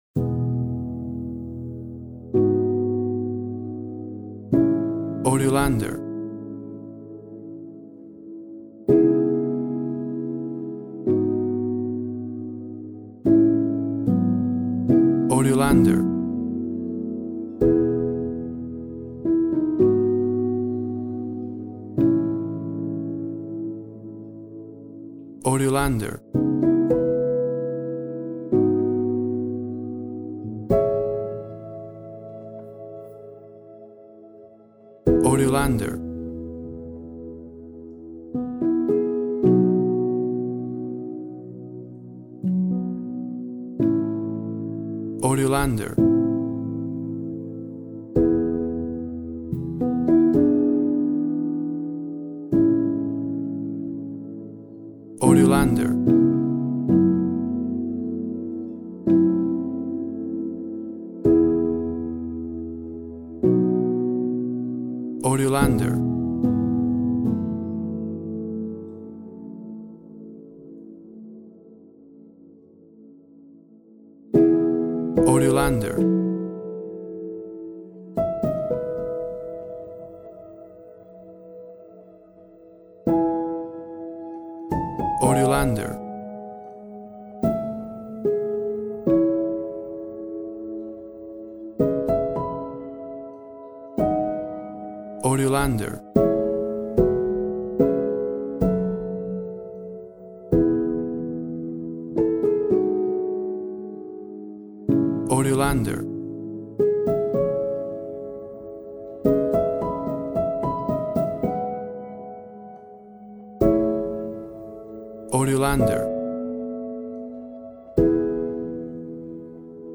Soft piano piece with a romantic and sublime character.
Tempo (BPM) 60